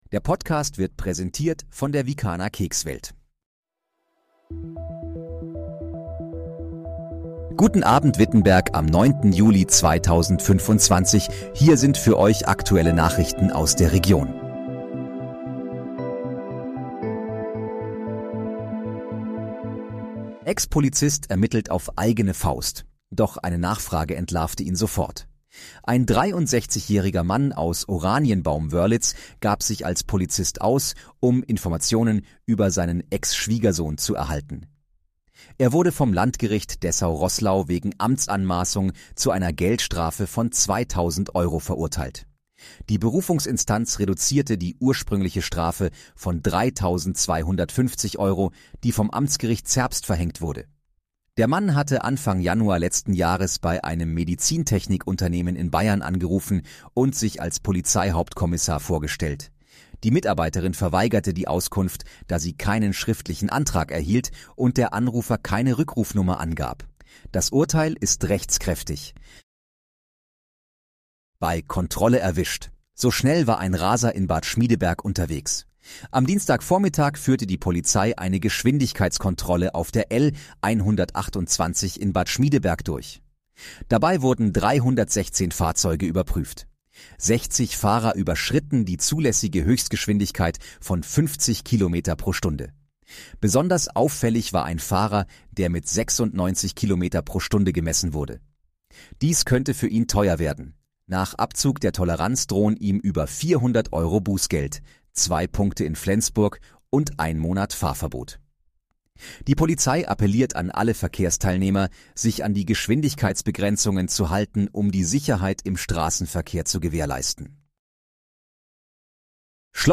Guten Abend, Wittenberg: Aktuelle Nachrichten vom 09.07.2025, erstellt mit KI-Unterstützung
Nachrichten